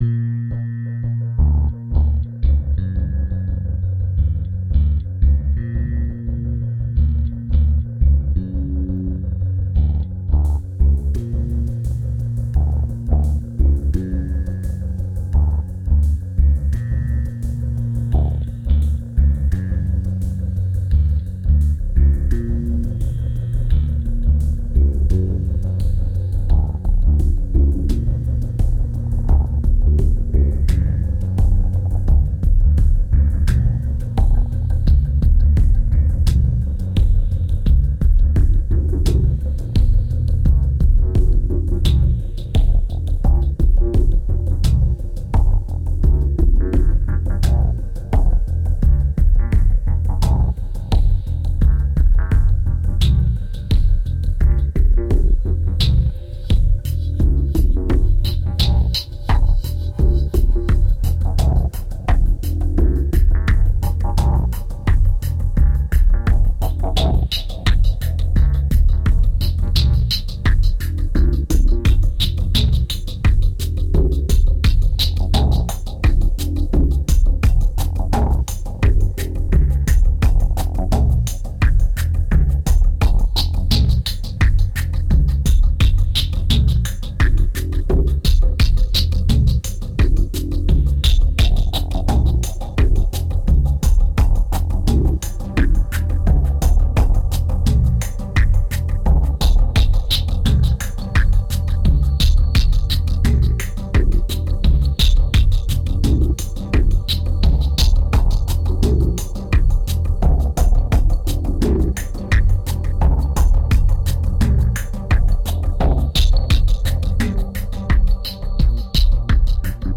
Drowning under massive FX, there still is a part of truth.